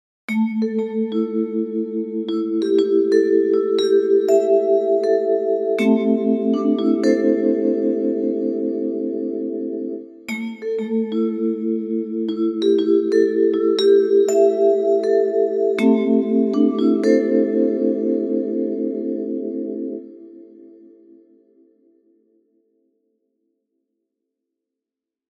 Ich habe bei allen Hörbeispielen auf externe Effekte und Summenkompression verzichtet. Es wurden nur Effekte des Kontakt-Instruments SONICCOUTURE VIBRAPHONE eingesetzt.
Beim nächsten Beispiel ist zuerst eine Phasendrehung, dann ein leichter Detune-Effekt im Spiel:
03_Vib_PhaseSTflip-PhaseDetune.mp3